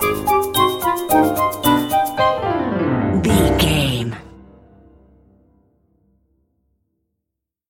Aeolian/Minor
orchestra
piano
percussion
horns
silly
circus
goofy
comical
cheerful
perky
Light hearted
quirky